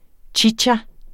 Udtale [ ˈtjitja ]